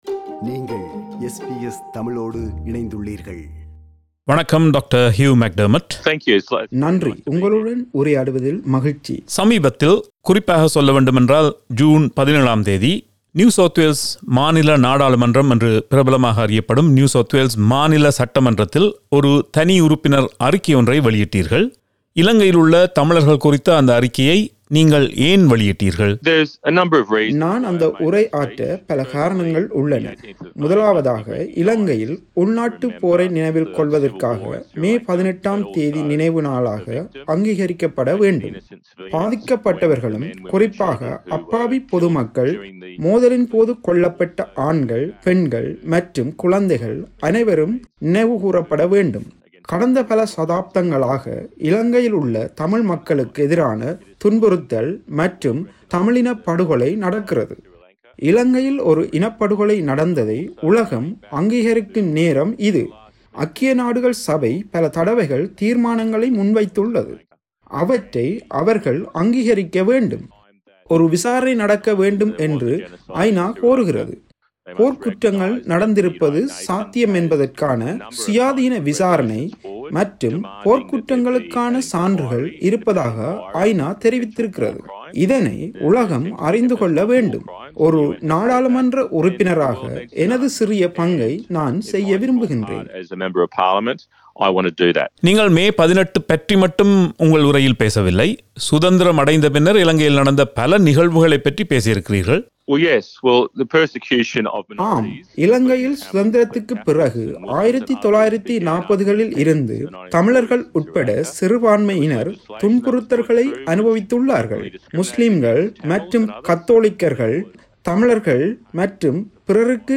Dr Hugh McDermott உடன் நடத்திய நேர்காணல்.